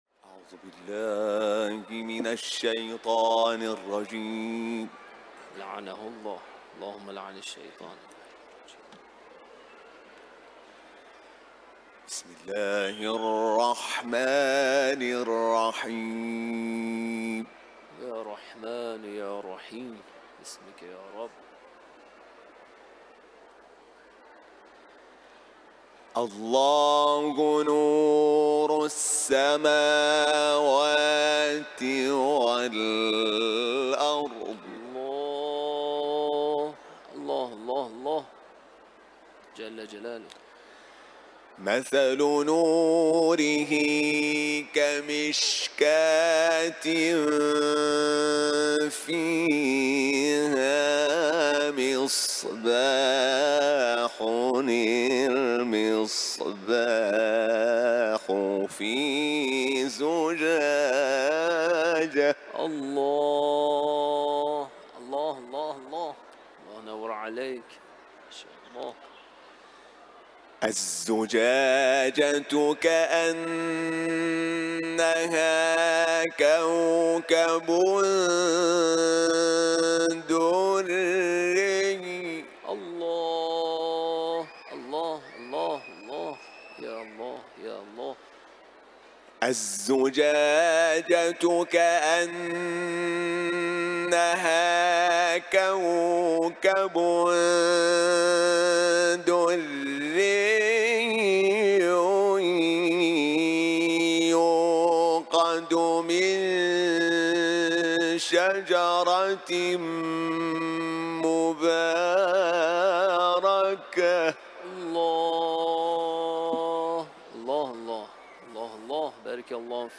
Kur’an tilaveti
İranlı Uluslararası Kur’an kârisi
İmam Rıza Türbesinde düzenlenen Kur’an toplatısında Beled ve Nur suresinden ayetler tilavet etti.